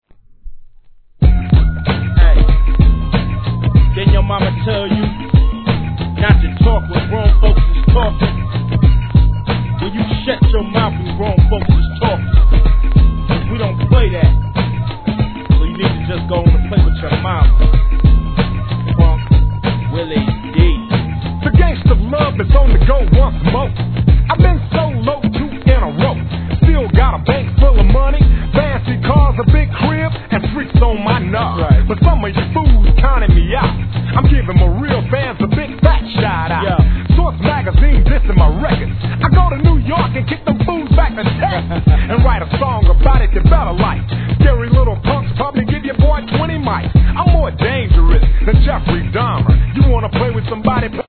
HIP HOP/R&B
女性コーラスが絡むメロディアスなトラックでの人気曲!!